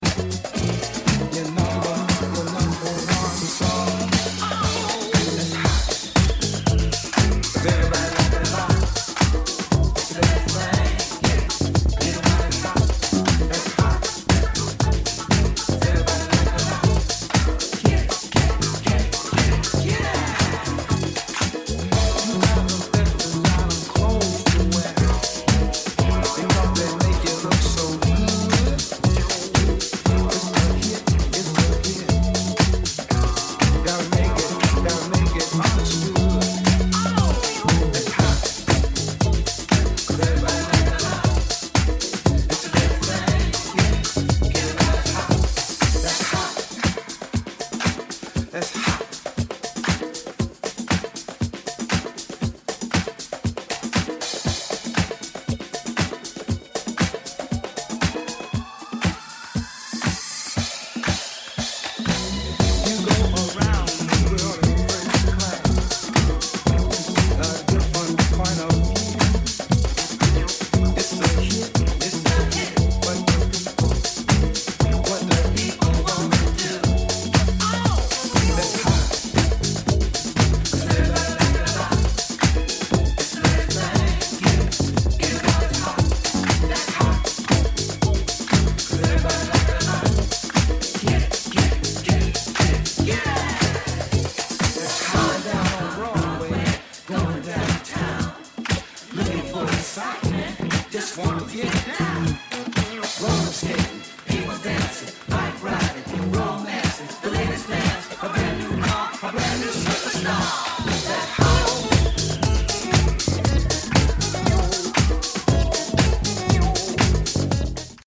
Disco / Balearic Edit